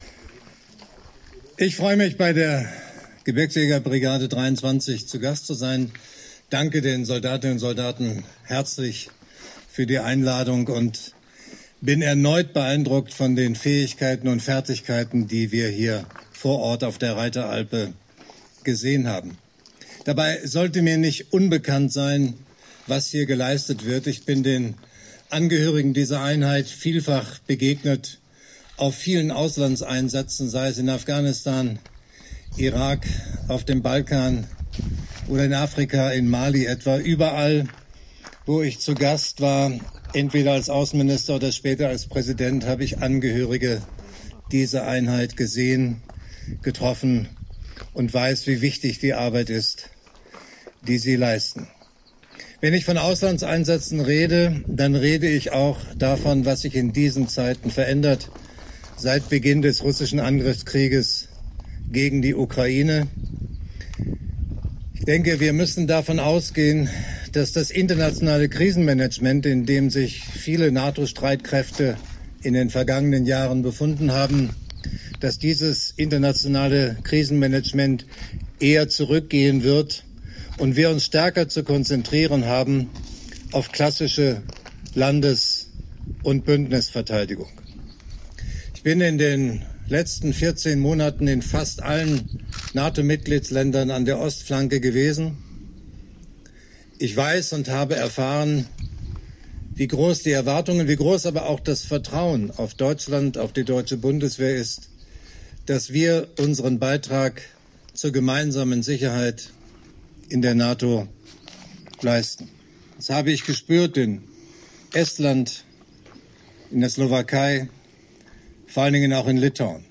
Statement des Bundespräsidenten Frank-Walter Steinmeier anlässlich seines Besuches bei der Gebirgsjägerbrigade 23